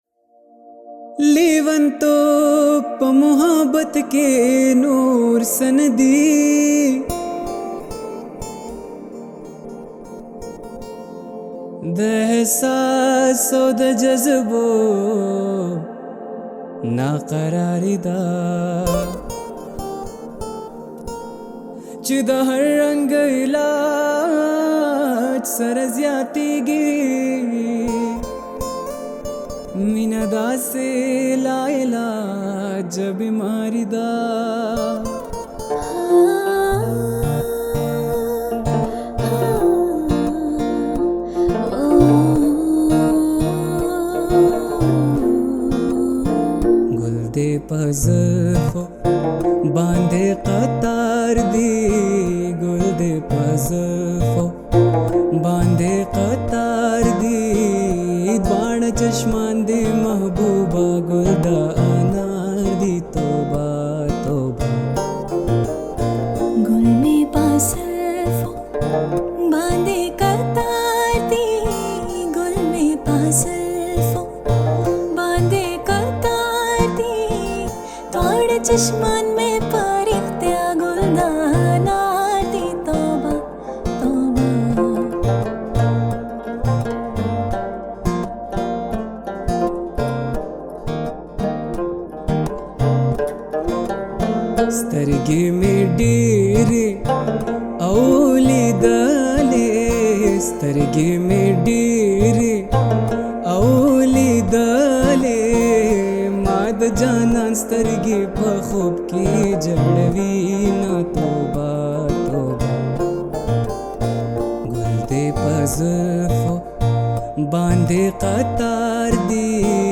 pashto song